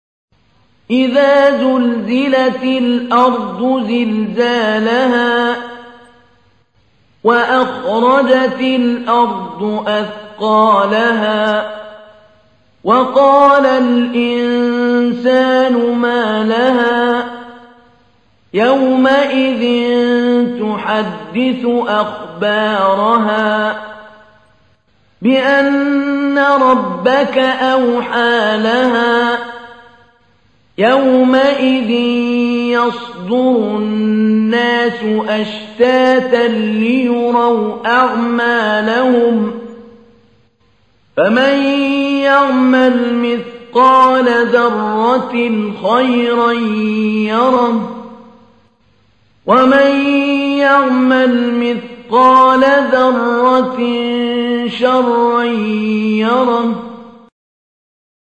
تحميل : 99. سورة الزلزلة / القارئ محمود علي البنا / القرآن الكريم / موقع يا حسين